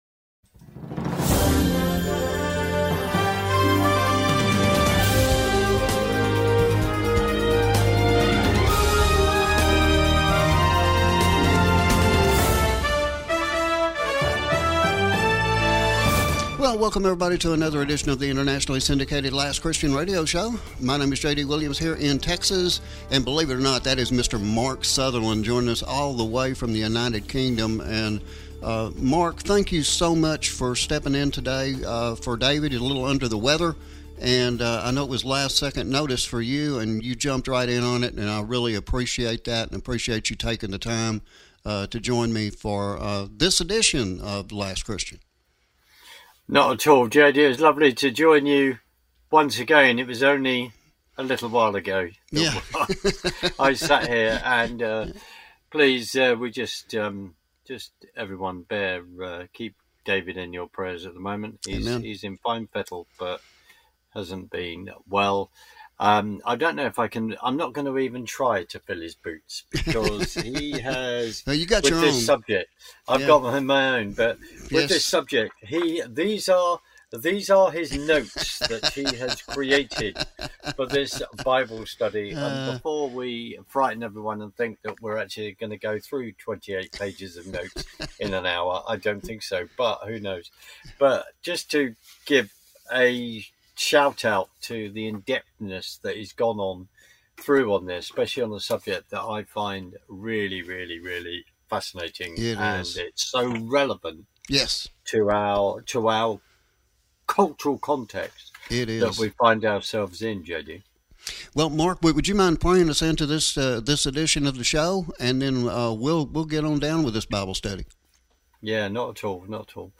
from the Studio of KRRB Revelation Radio